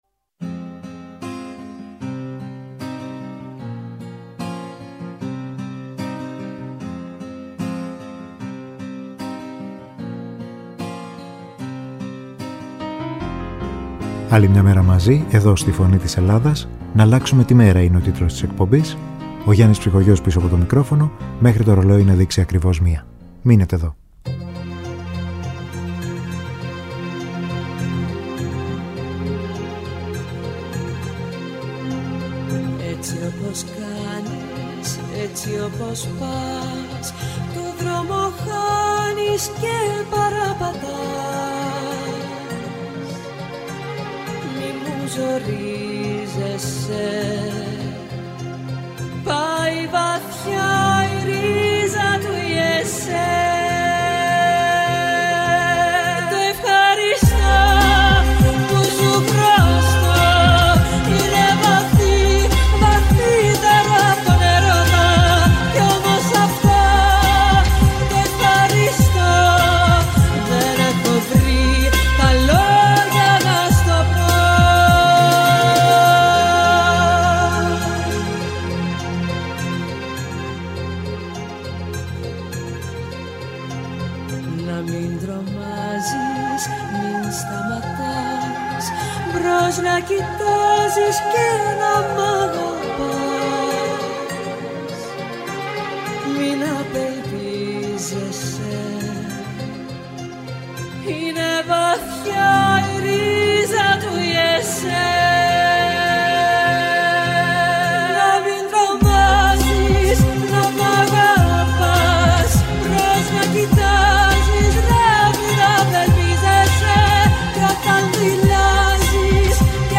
Μουσική